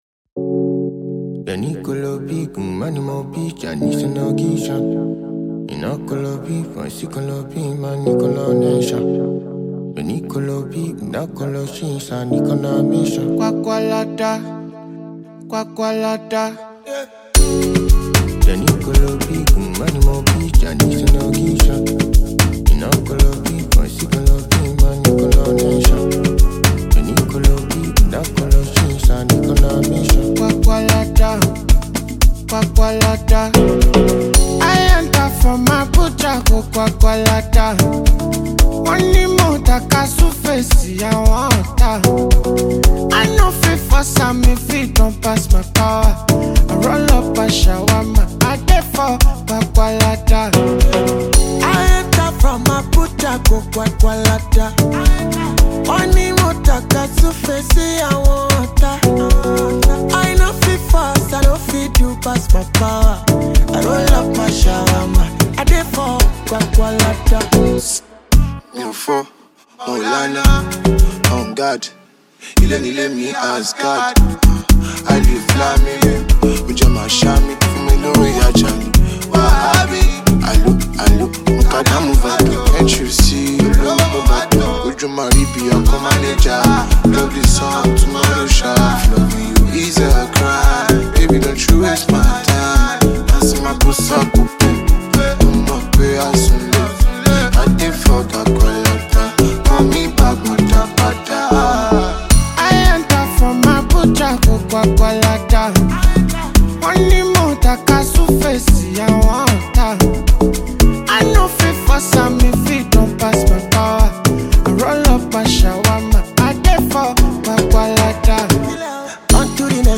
Nigerian Afro-fusion singer, songwriter and record producer
Afrobeat